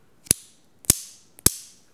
briquet_01.wav